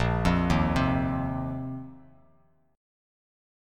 A#sus2sus4 chord